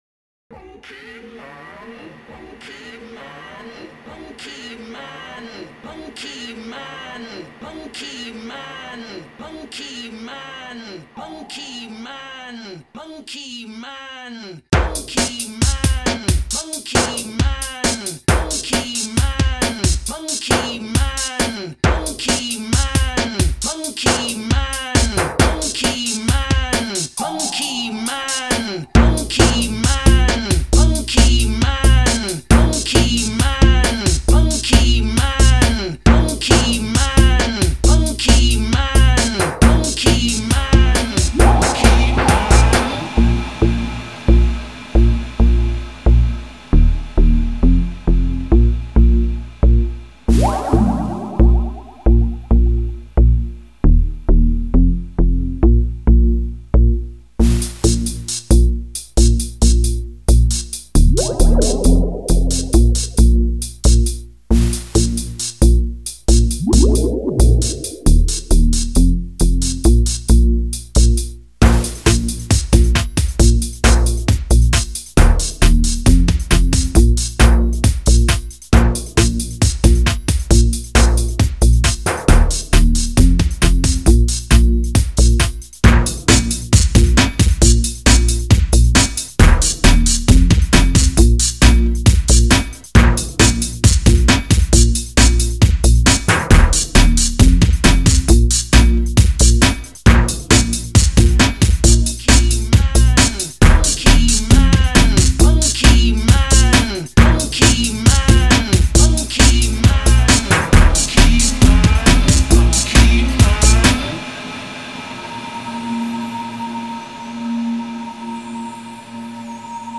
Sample-based breakbeat heaven
a thumping breaks treatment of an old skool reggae classic